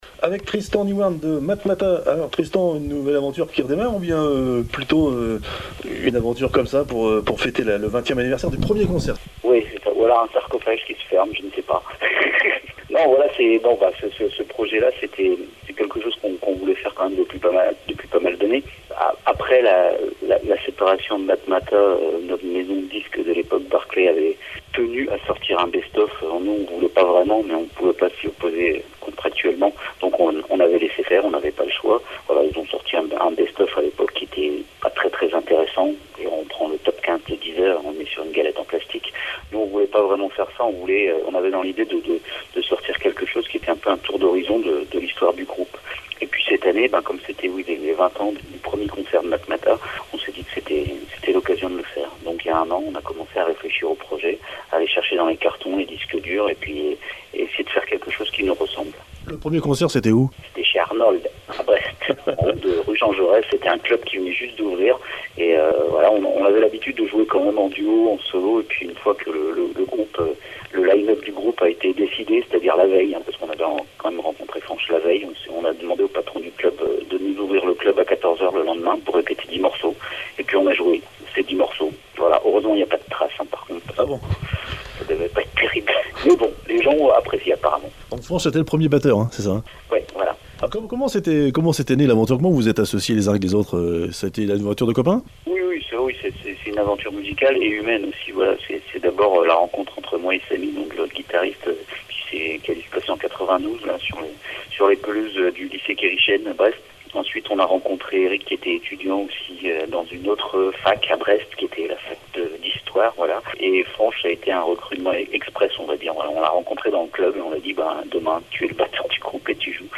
Interview de Tristan Nihouarn (Stan) Chanteur fondateur de Matmatah  (à écouter également : Apéro Rock Spécial Matmatah : 1ère diffusion le 31 octobre 2016 sur Radio Korrigans et Radio Larg)